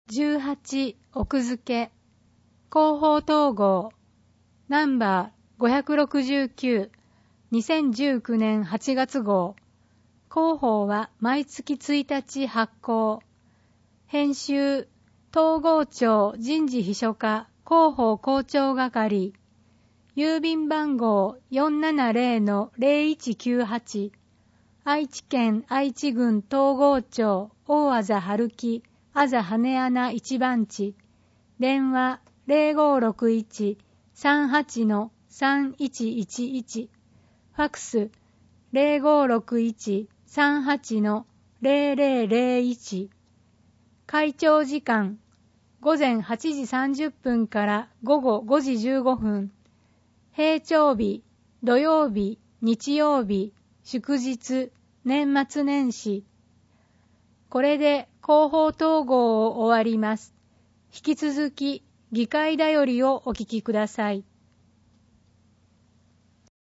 広報とうごう音訳版（2019年8月号）